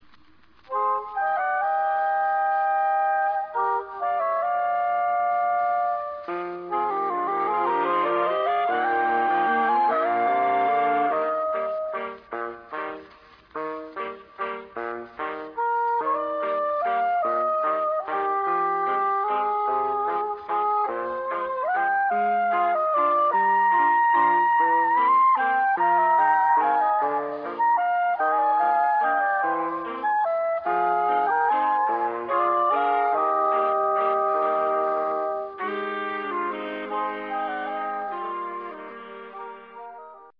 ソプラノＳＡＸを中心としたメロディーシーンの合体！
怖いくらいに悩ましいＳＡＸの誘惑...。
all written & sax